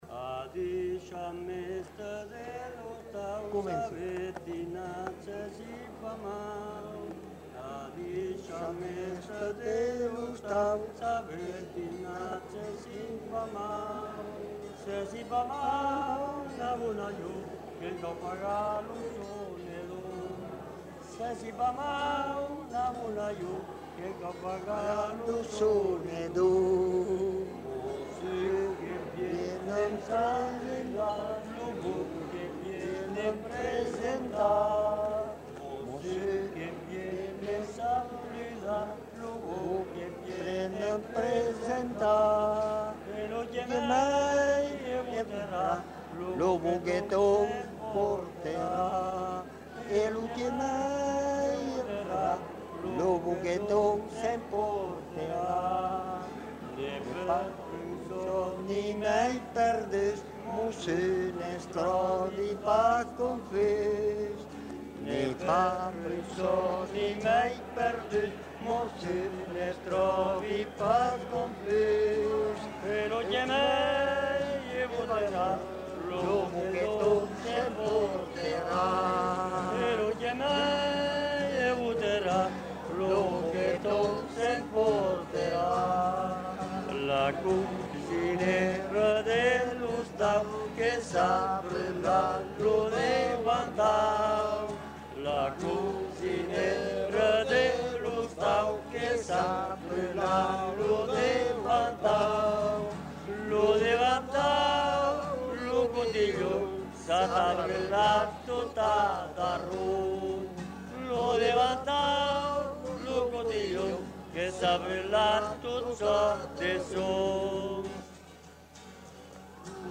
Lieu : Saint-Sever
Genre : chanson-musique
Effectif : 3
Type de voix : voix d'homme
Production du son : chanté
Instrument de musique : guitare